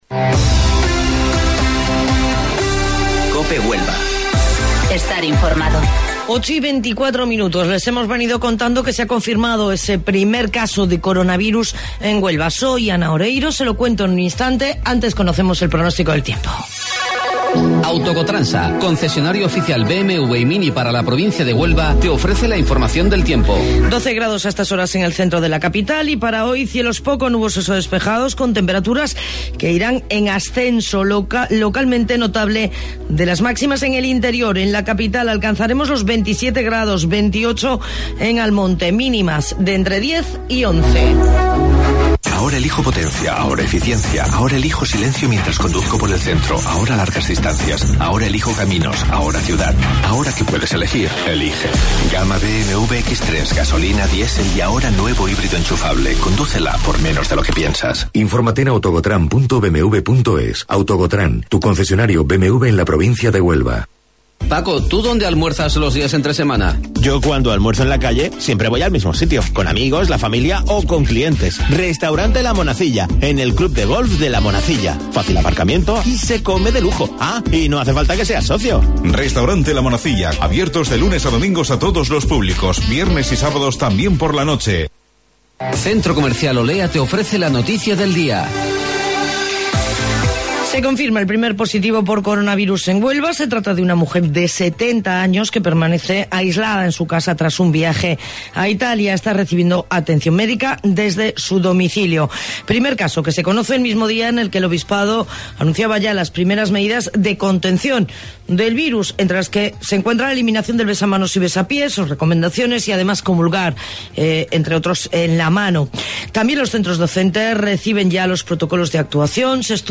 AUDIO: Informativo Local 08:25 del 10 Marzo